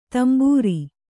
♪ tambūri